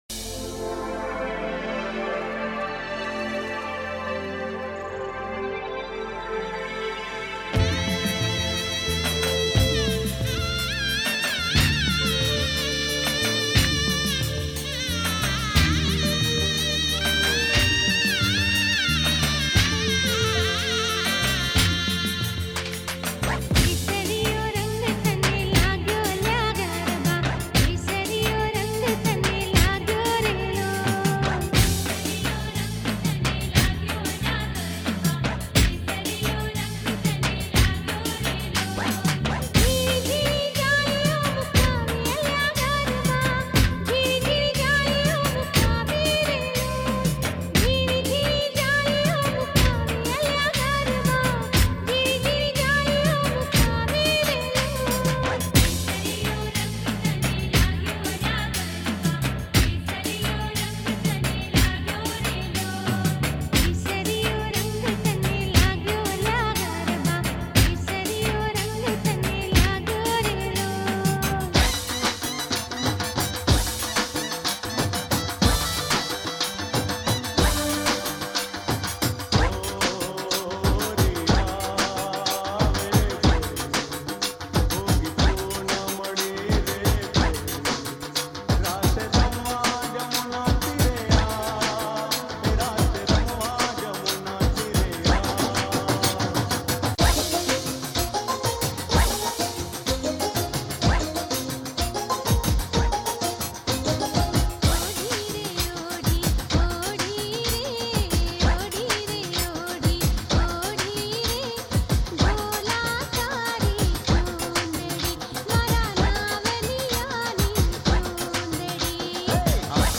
Navratri Garba Albums